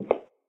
inside-step-2.ogg